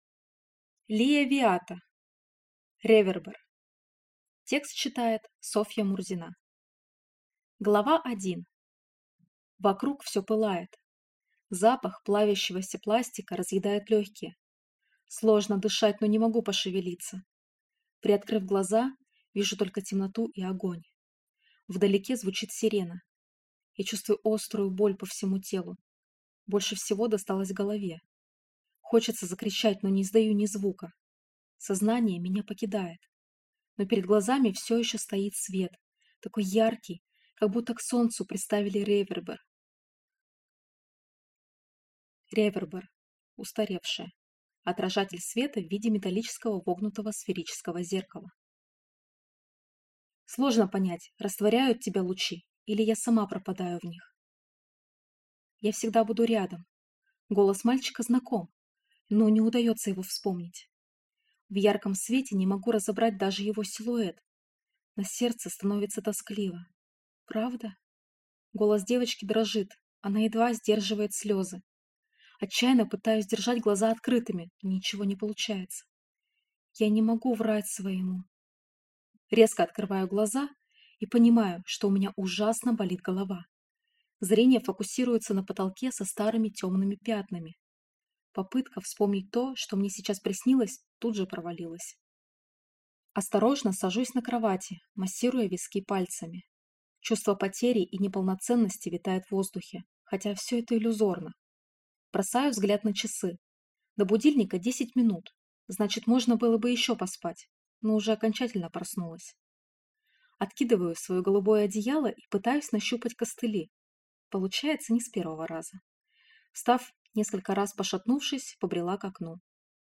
Аудиокнига Ревербер | Библиотека аудиокниг